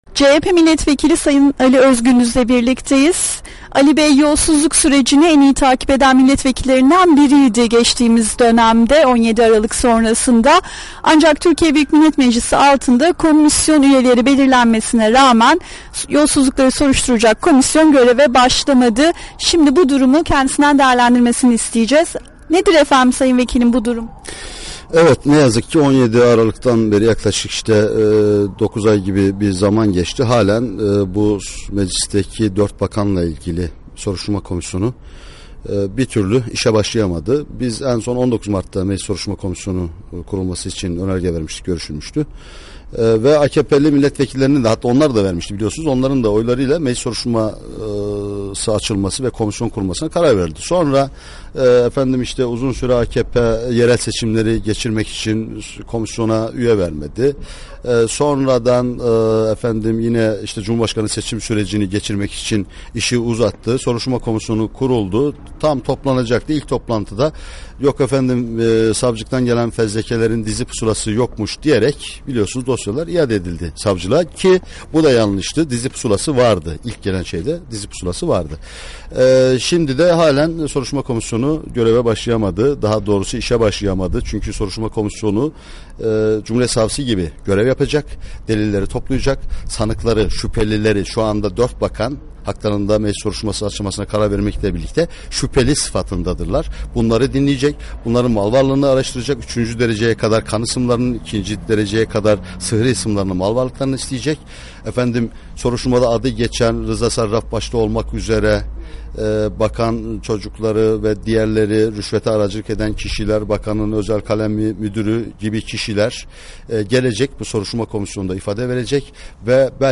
Ali Özgündüz ile Söyleşi